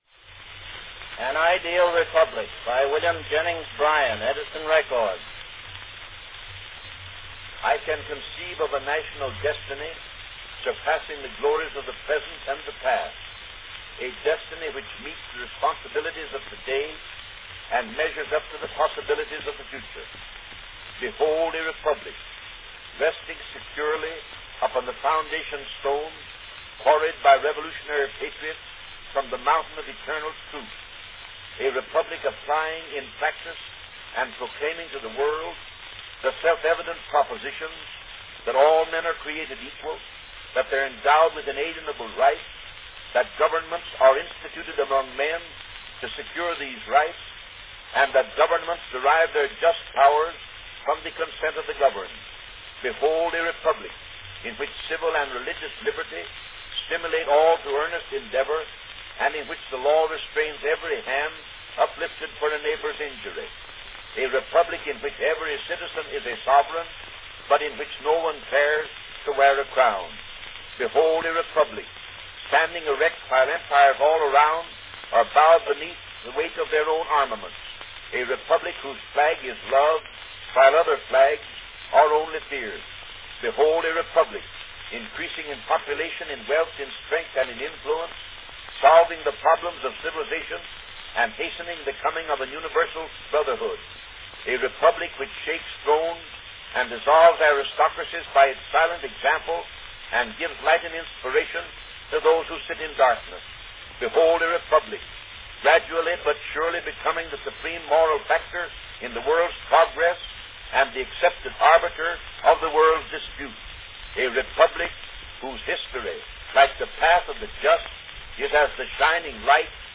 Category Talking
Performed by William Jennings Bryan
Announcement "An Ideal Republic, by William Jennings Bryan.   Edison record."
Interested in hearing other two-minute wax cylinder recordings by William Jennings Bryan and William H. Taft?